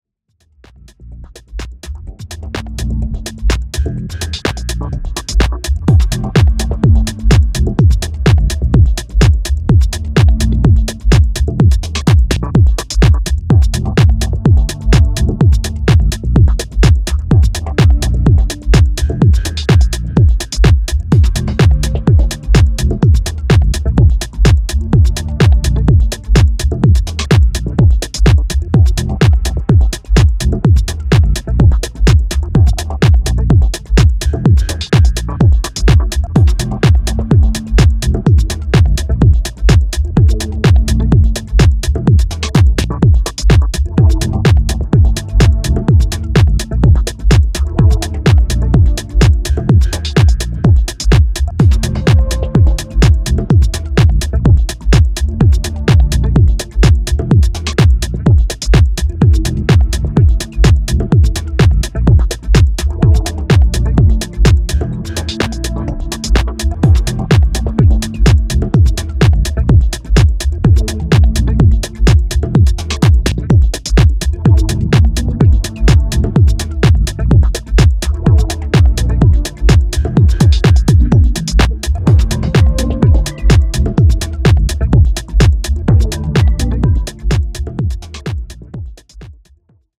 フリップの2曲は、一段とテンションを落としたミニマル・ハウス路線の仕上がり。